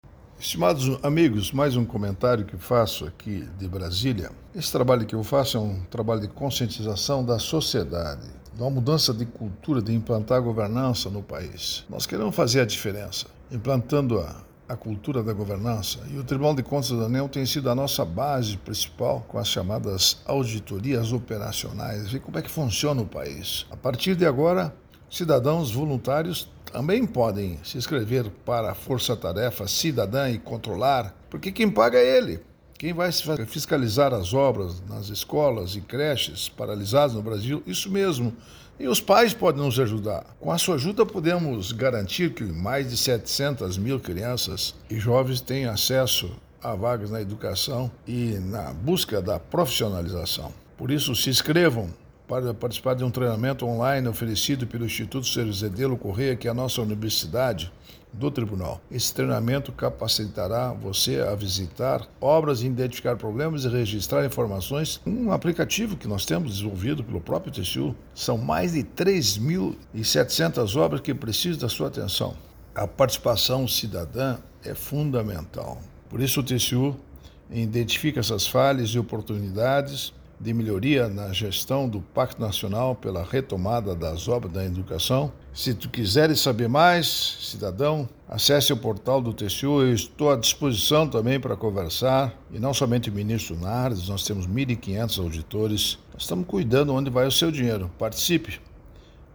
Comentário de Augusto Nardes, ministro TCU.